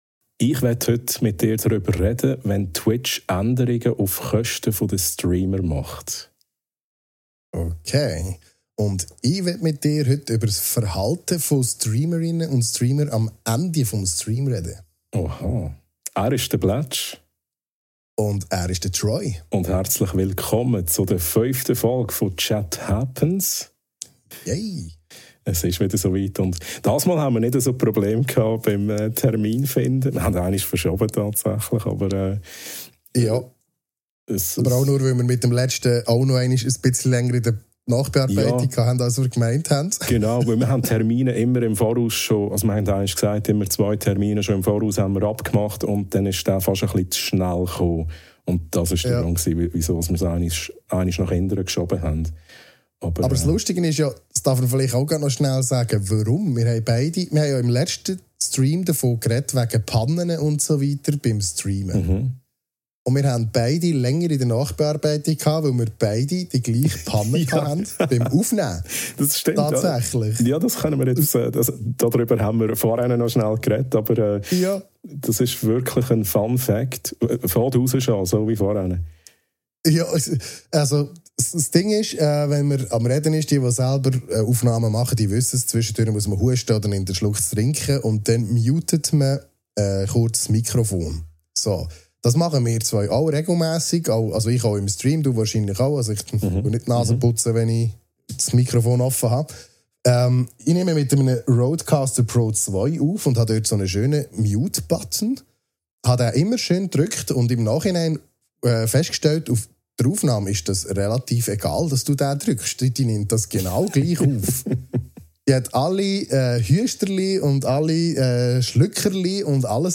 Schweizerdeutsch, kritisch und neugierig – viel Spass mit Episode 5!